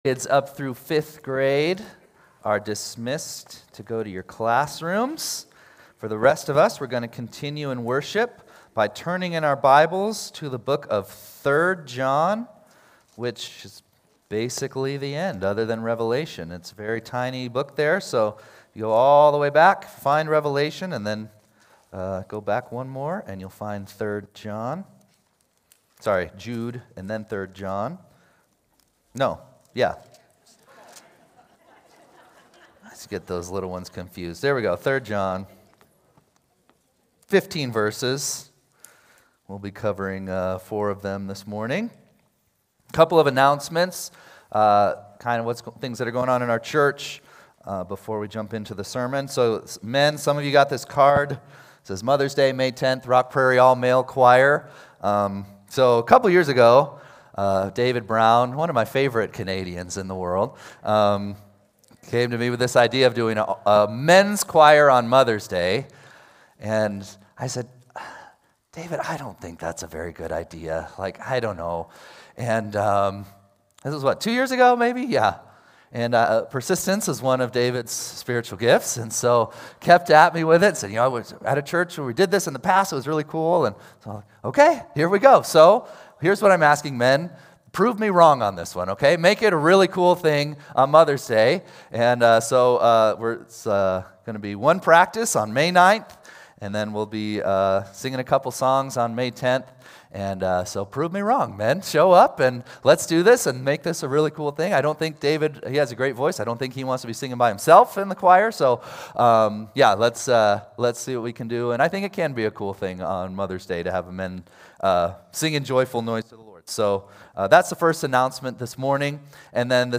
4-19-26-Sunday-Service.mp3